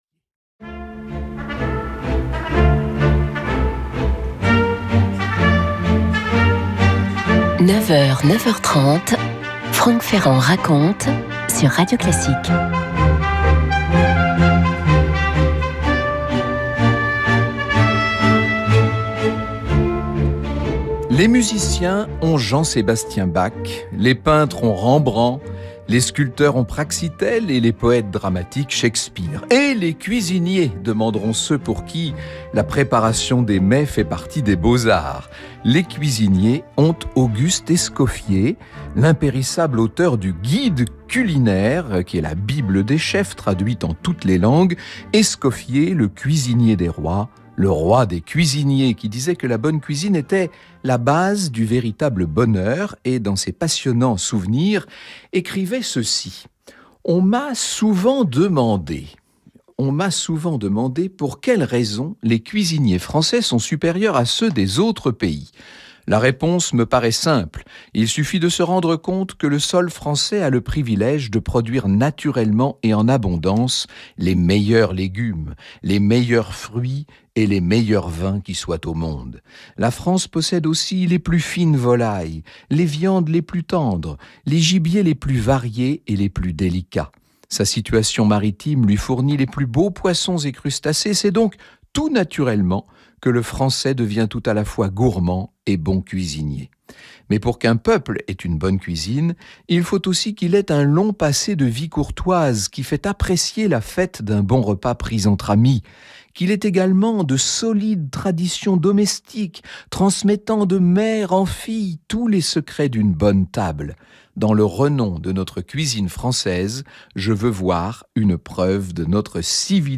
Par Franck Ferrand - Radio Classique Émission : Franck Ferrand Raconte 1er avril 2020 À 9h, et 14h Franck Ferrand raconte l’Histoire avec un enthousiasme et un talent plébiscités par les auditeurs de Radio Classique.